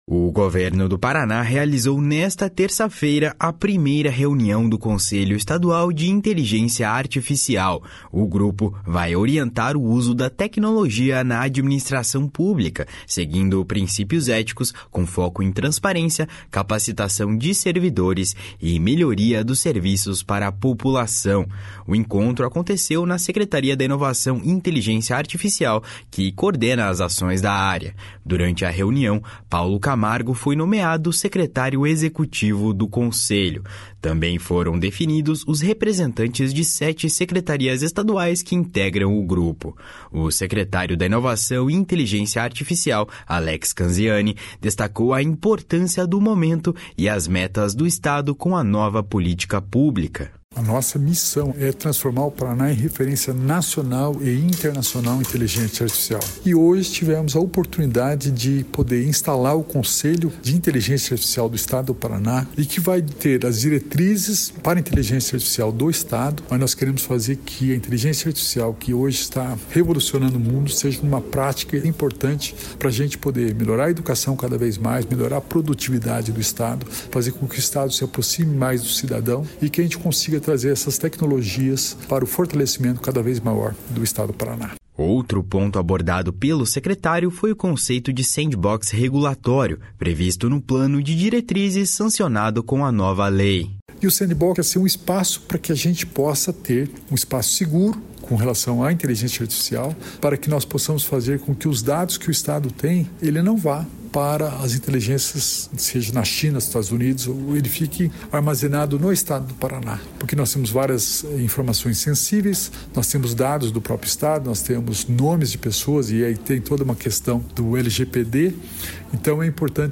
// SONORA ALEX CANZIANI //
O secretário de Ciência, Tecnologia e Ensino Superior, Aldo Bona, também ressaltou o papel estratégico do conselho.
Repórter